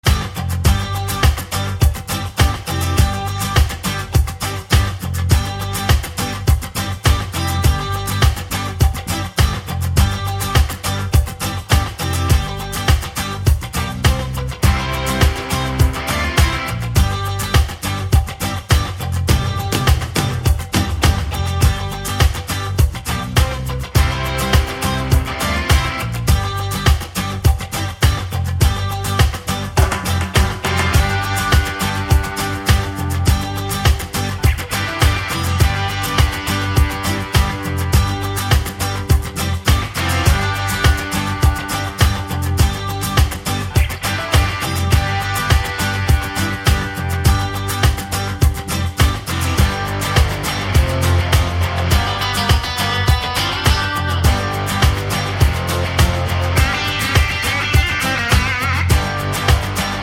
no Backing Vocals Pop (1990s) 3:34 Buy £1.50